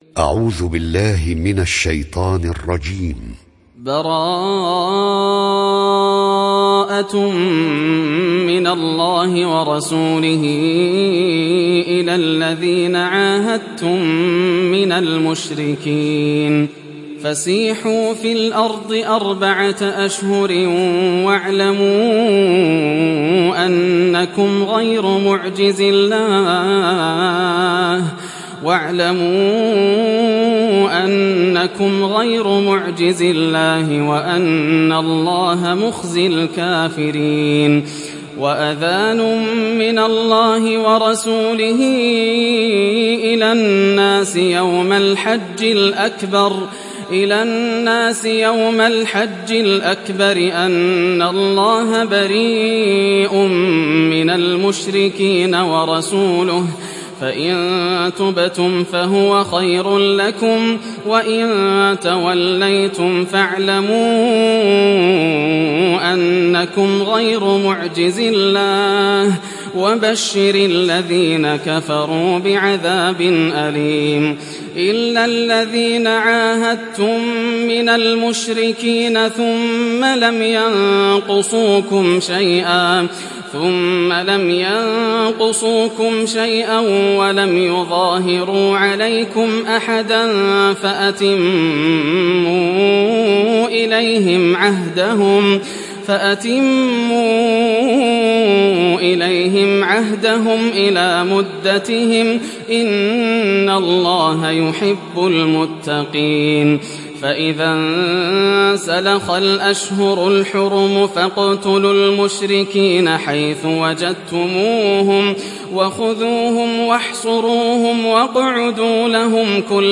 Surah At Tawbah mp3 Download Yasser Al Dosari (Riwayat Hafs)